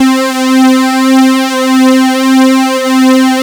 juno c2mono.wav